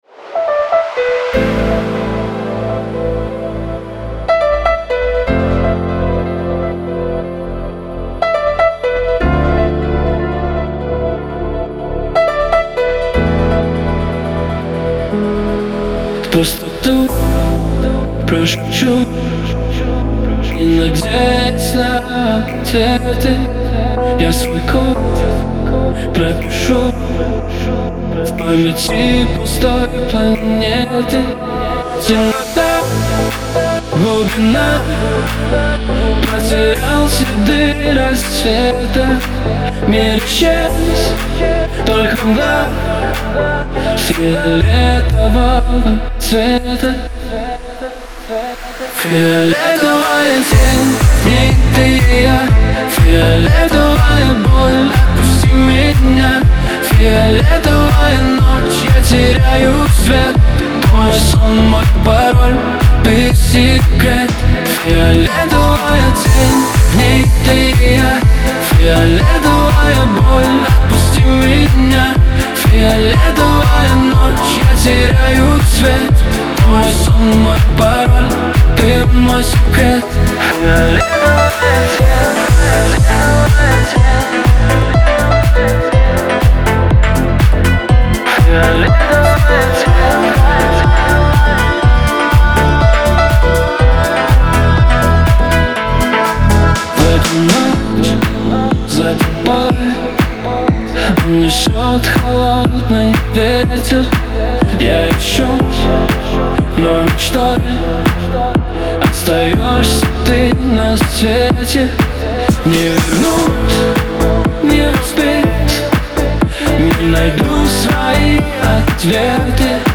эстрада , танцевальная музыка
pop